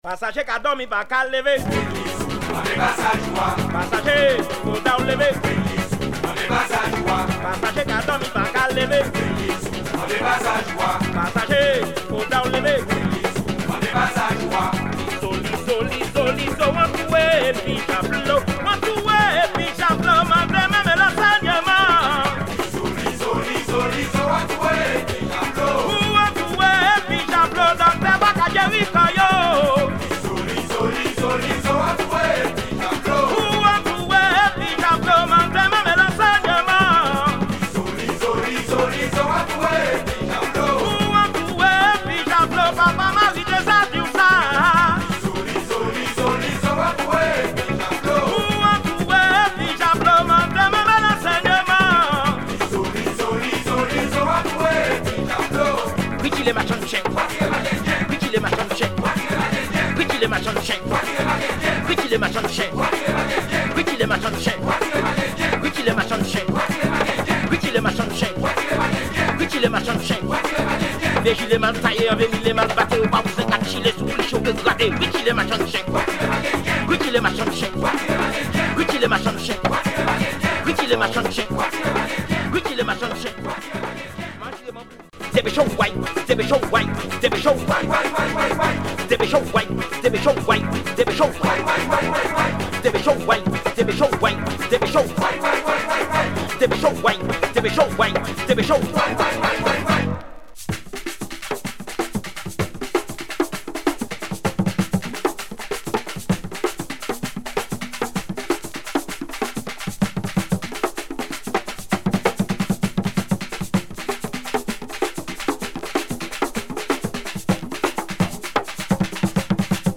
Modern gwo ka
with a heavy bass, as well as the organ driven